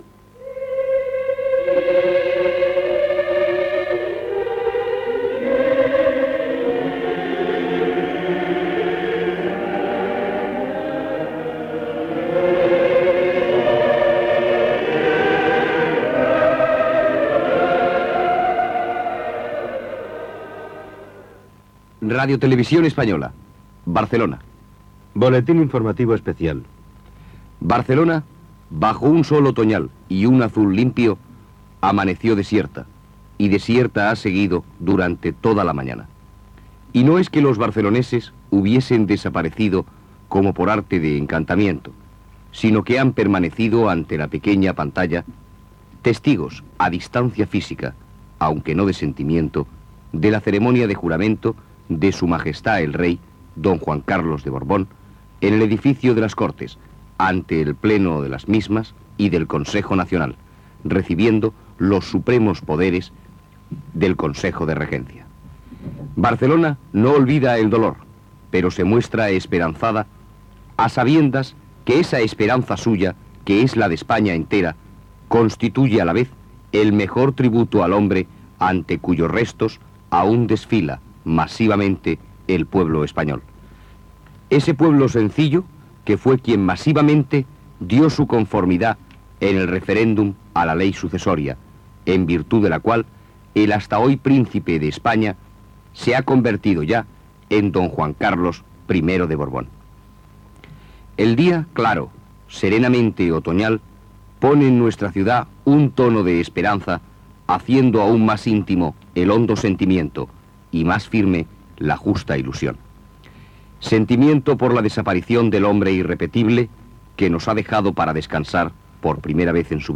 Boletín informativo especial
Cròniques des de Palma de Mallorca, Lleida, Girona sobre el publicat a la premsa.